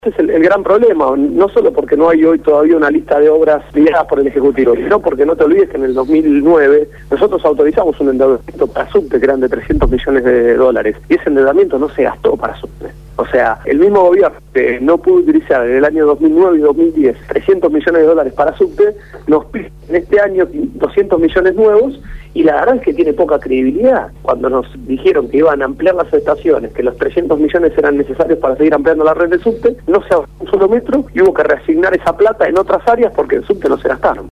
Gonzalo Ruanova, Diputado de la Ciudad de Buenos Aires por el Partido Nuevo Encuentro, habló sobre este tema en el programa “Desde el barrio” de Radio Gráfica FM 89.3